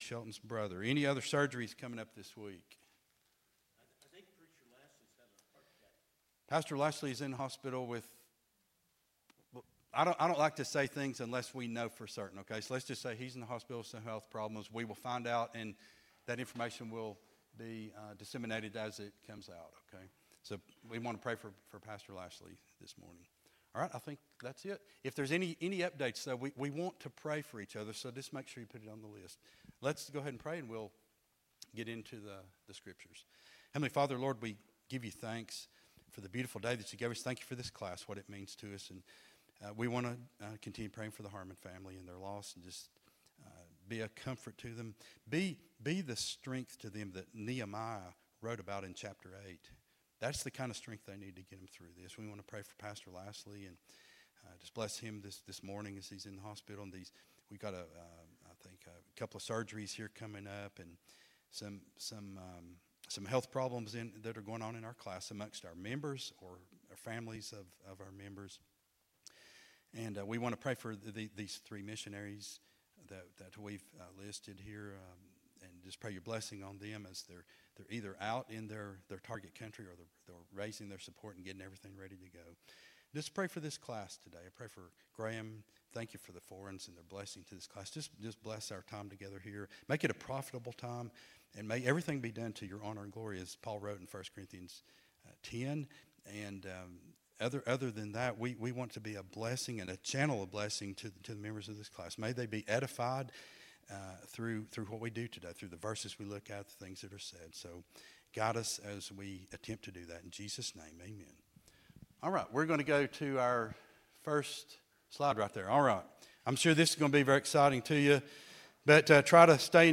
11-24-24 Sunday School Lesson | Buffalo Ridge Baptist Church